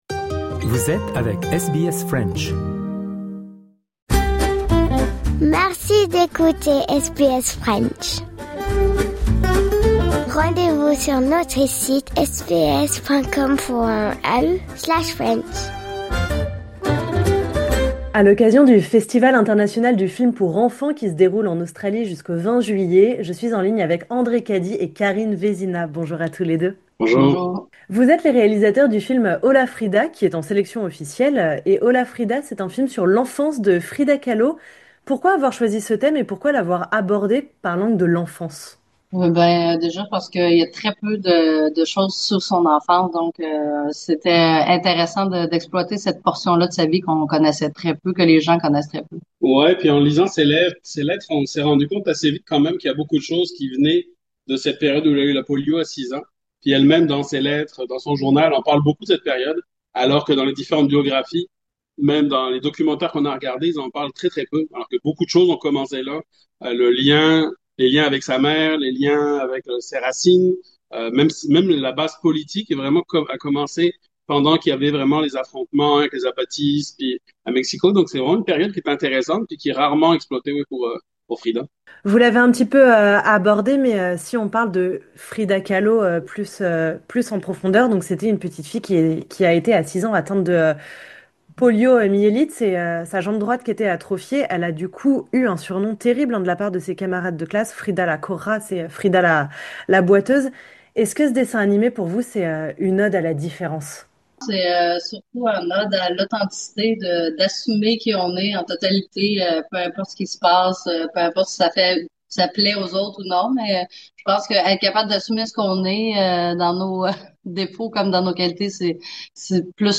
Rencontres